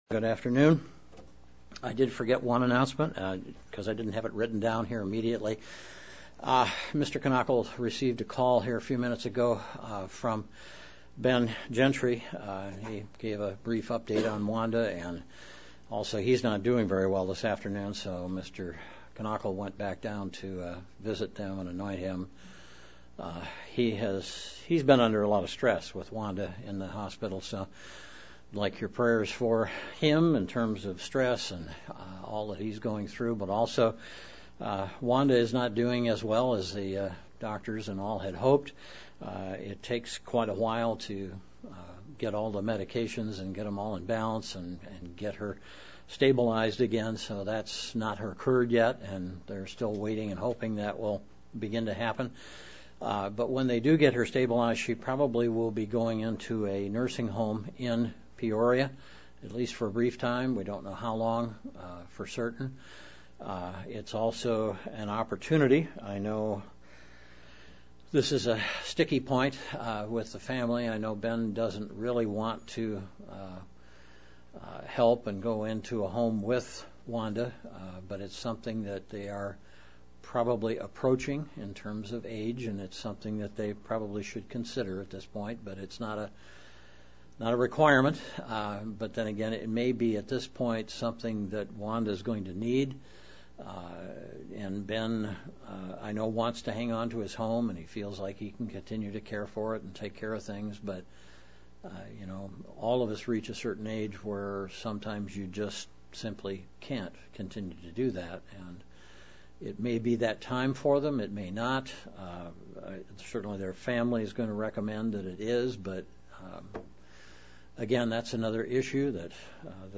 Given in Central Illinois
UCG Sermon Studying the bible?